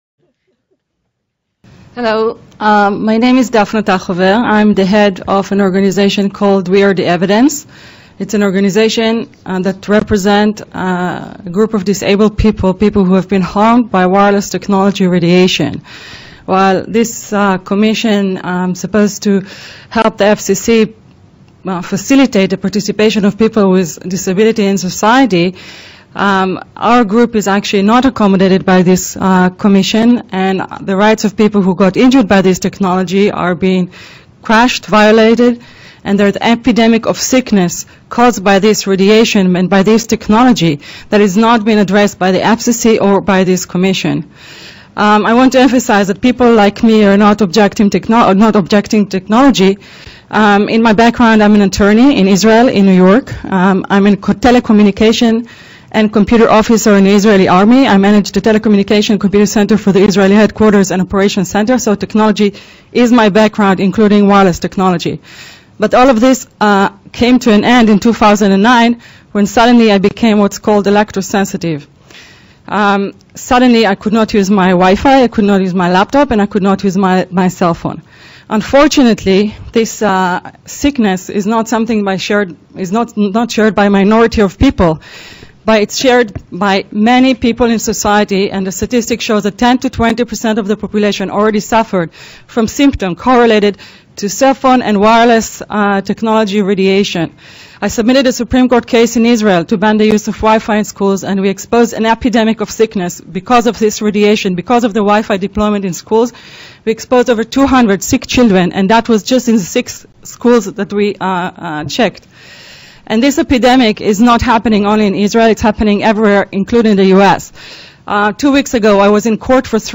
We Are The Evidence Testimony to the FCC Disability Committee on Wireless Radiation Harms